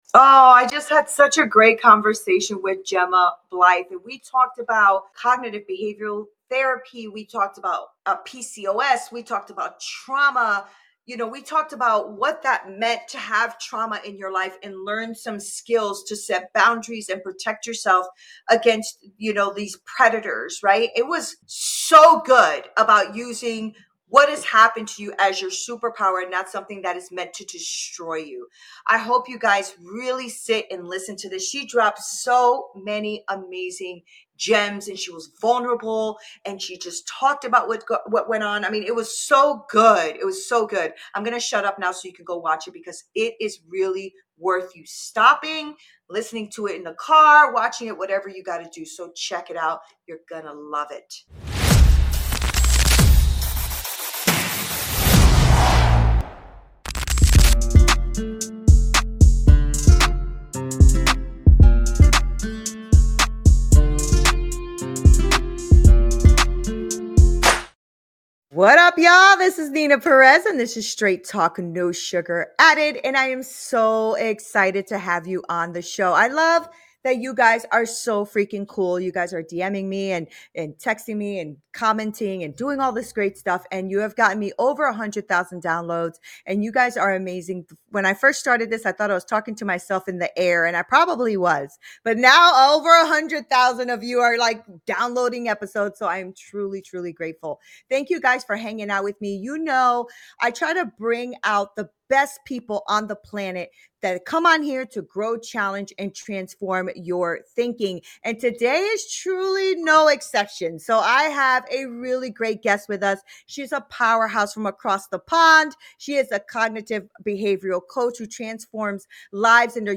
empathetic and empowering voice